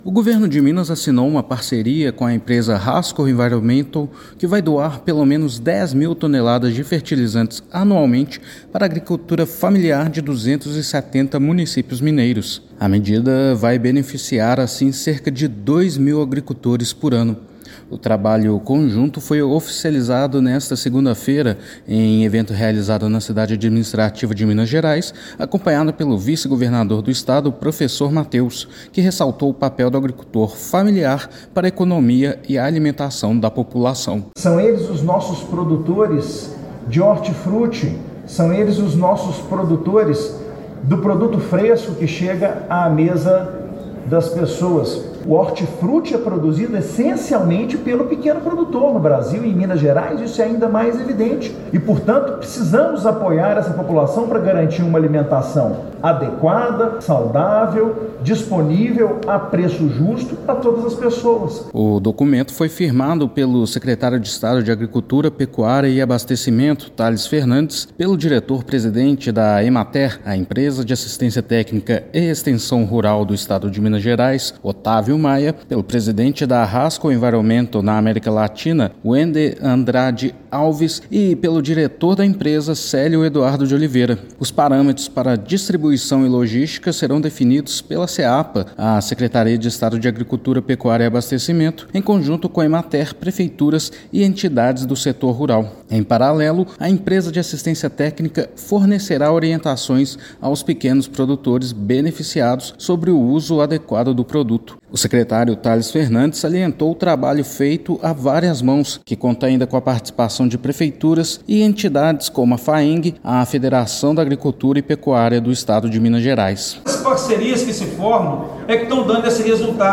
Seapa coordenará os trabalhos e Emater-MG prestará assistência técnica; distribuição para pequenos produtores de 270 cidades mineiras será definida com entidades do setor rural, prefeituras e iniciativa privada. Ouça matéria de rádio.